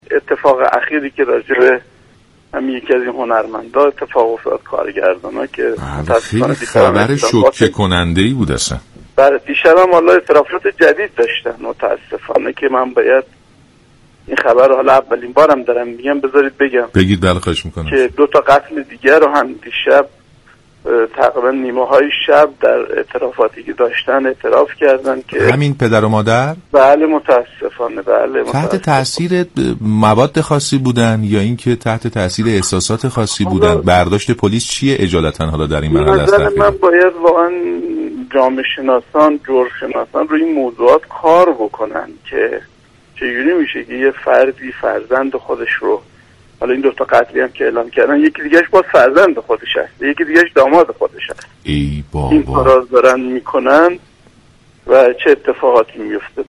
سردار رحیمی، رئیس‌پلیس تهران صبح امروز 29 اردیبهشت ماه، در گفتگو با برنامه زنده «سلام، صبح بخیر» درباره قتل هولناک بابک خرمدین، کارگردان سینما جزییات جدیدی را بازگو کرد که این بخش از برنامه رادیویی را می‌شنویم.